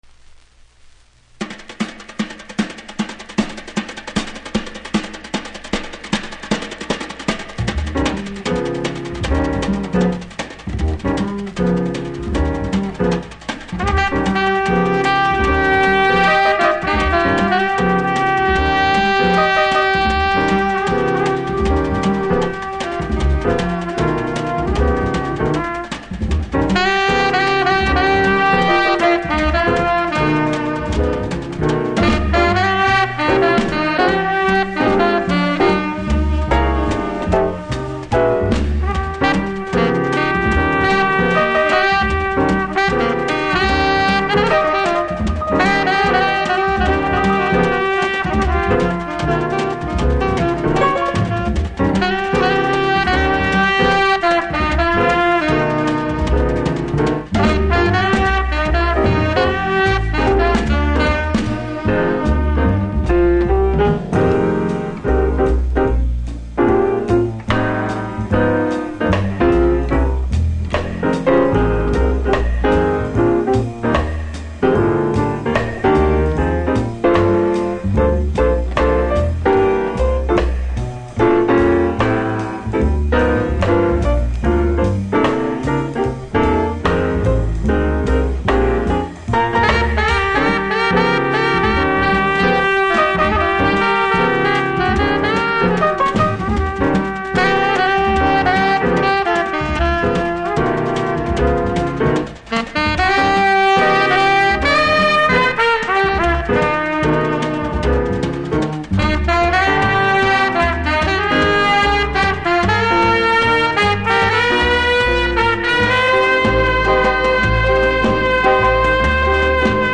INSTRUMENTAL
Vinyl
無録音部分にプレスノイズ感じますが音が始まれば感じないので問題無しでしょう。